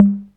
Tom [ Pick Up ].wav